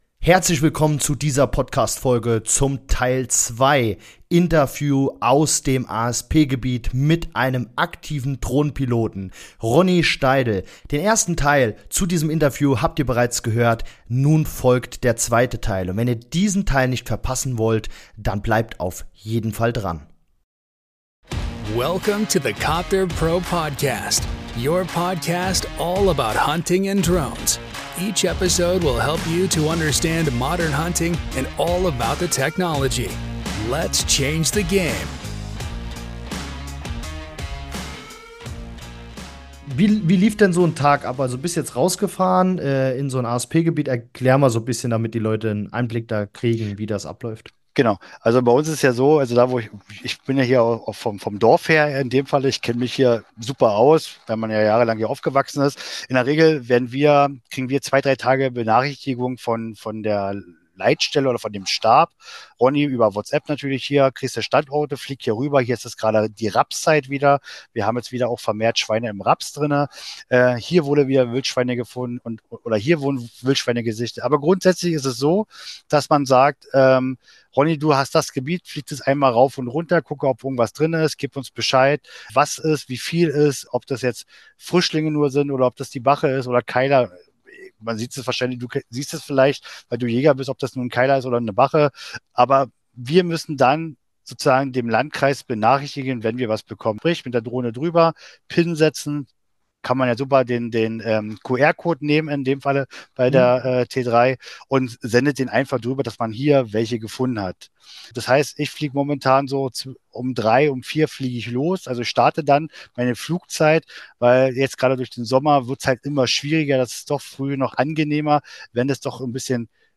#111 Interview mit Drohnenpilot aus einem ASP-Gebiet - So wird die Drohne eingesetzt! [2/2] ~ Copterpro Podcast: Alles zum Thema Drohnen und moderne Jagd Podcast